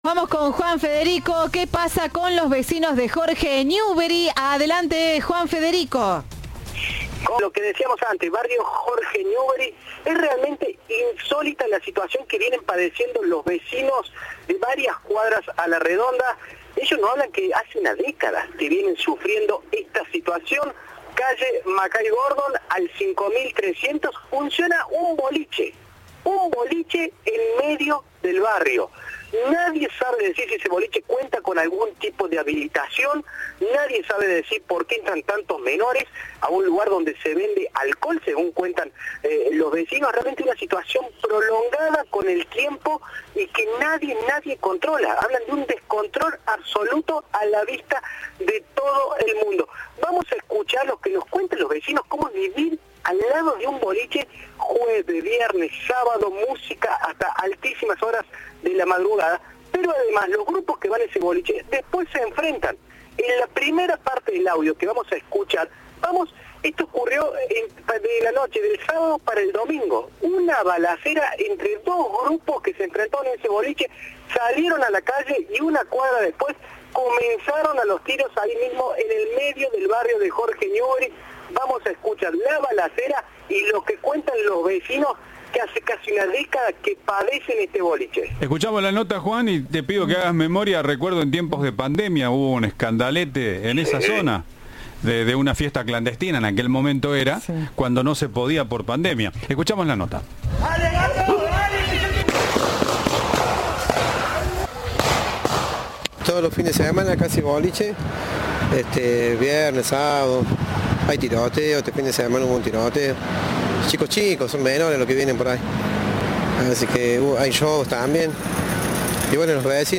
"En plena pandemia fue cerrado, es un espacio donde asisten muchos menores y se vende alcohol", afirmaron vecinos del sector en diálogo con Cadena 3.
Un vecino describió la incomodidad de vivir al lado de un lugar donde todos los fines de semana se realizan fiestas y se producen enfrentamientos.